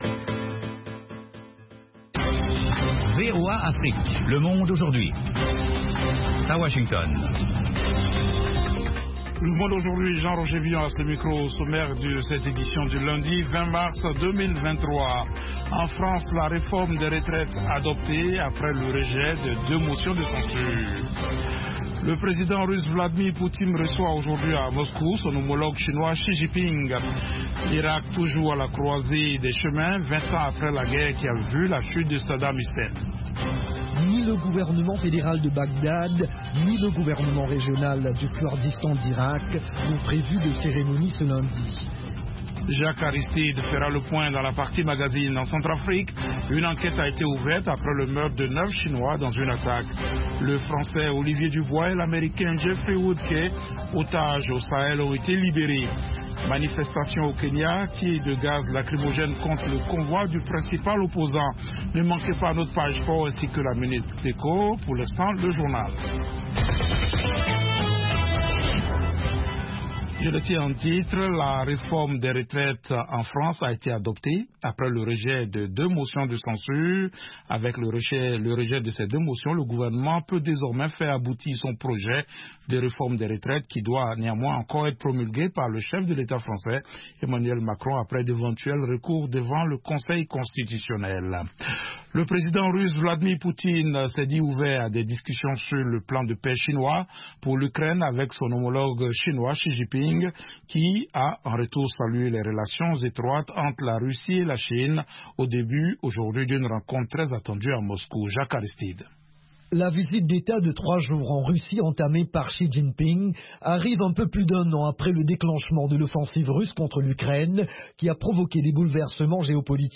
Le programme phare du service francophone dure 30 minutes sur les informations de dernières minutes, des reportages de nos correspondants, des interviews et analyses sur la politique, l’économie, les phénomènes de société et sur la société civile.